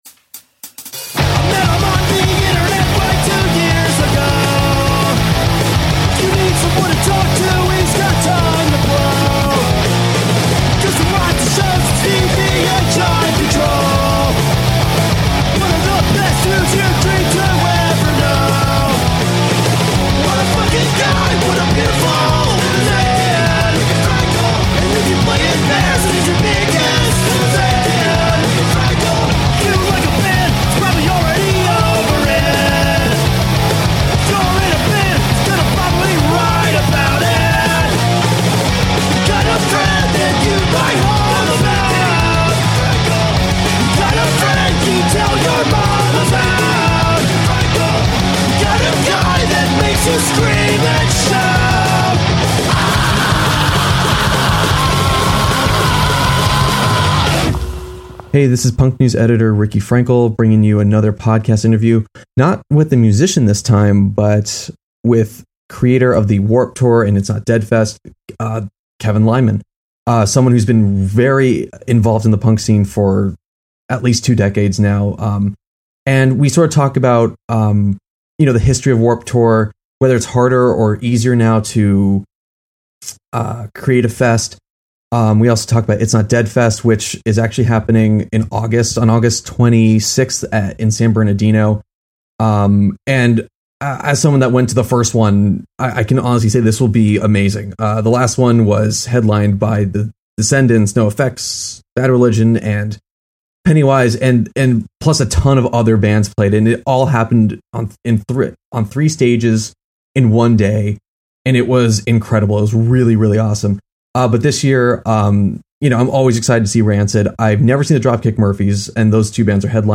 Bonus - Interview with Kevin Lyman